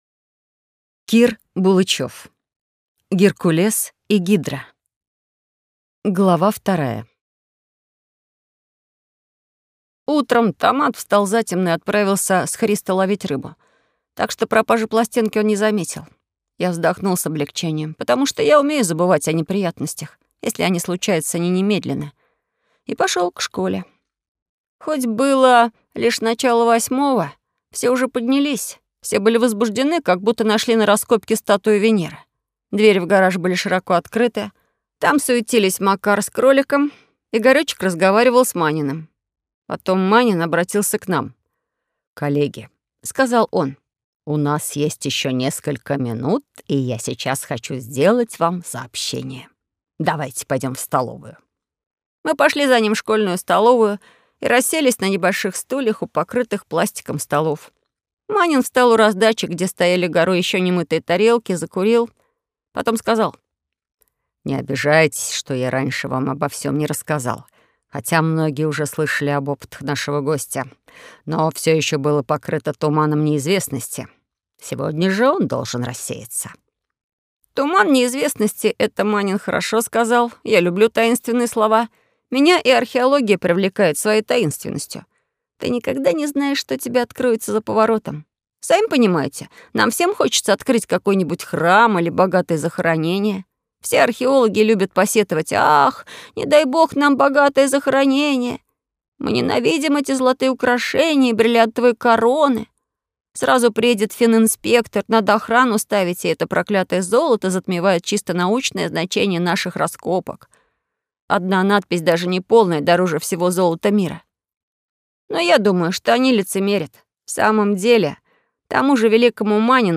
Аудиокнига Геркулес и Гидра. Черный саквояж. Речной доктор | Библиотека аудиокниг